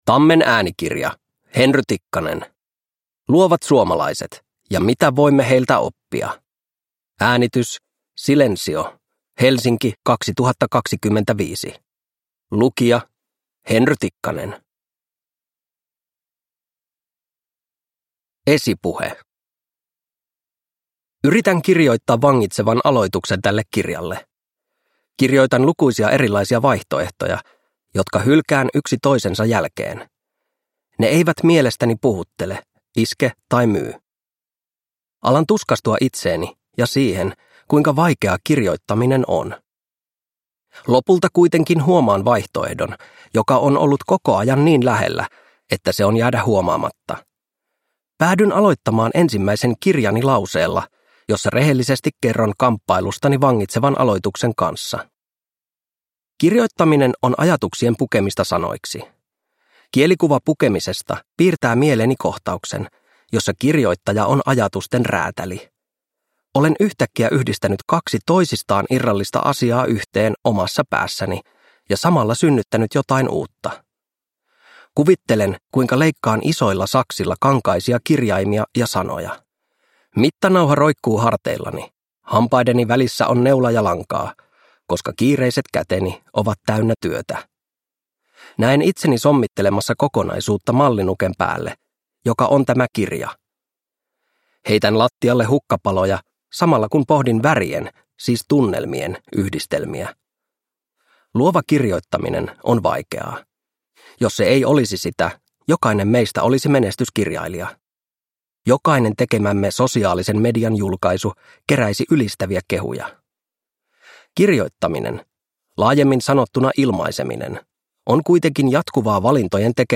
Luovat suomalaiset – Ljudbok